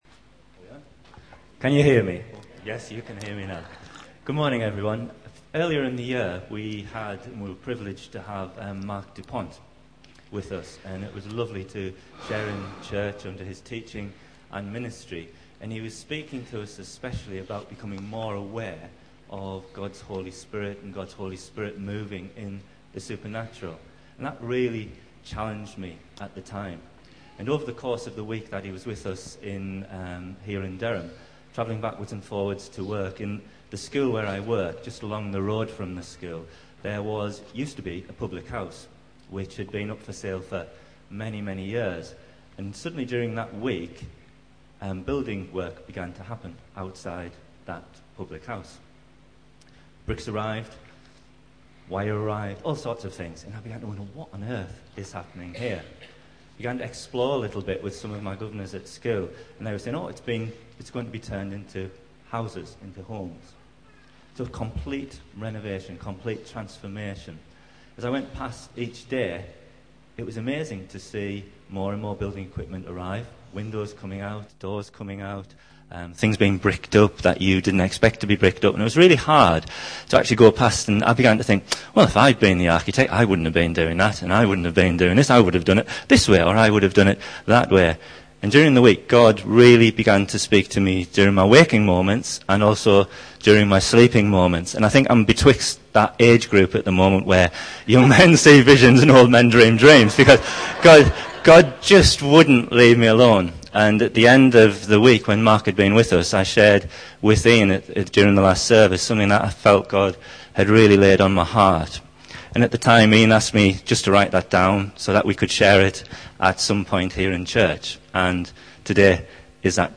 Various sermons preached at Emmanuel Church Durham during 2013.